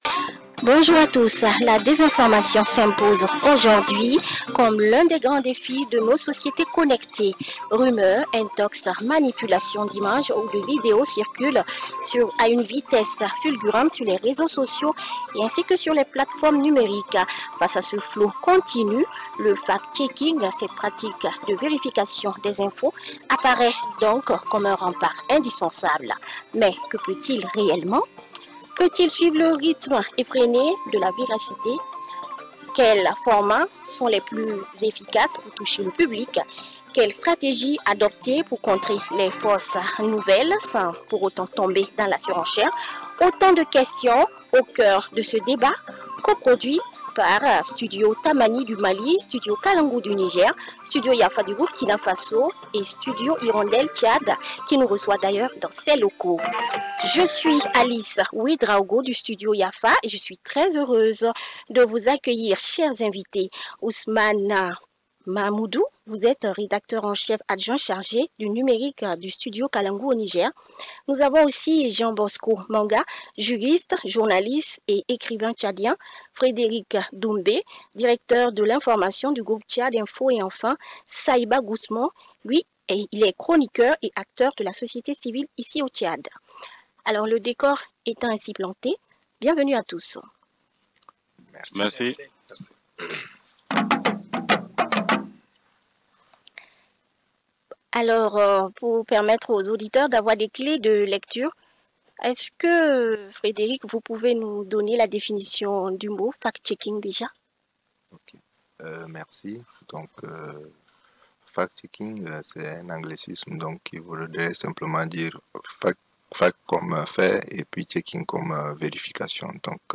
Désinformation en Afrique : Un débat régional sur le rôle du fact-checking ( 1ère partie ) - Studio Tamani - Informations, débats, magazines : toute l’actualité du Mali, en 5 langues
Pour en débattre, quatre invités issus des médias et de la société civile :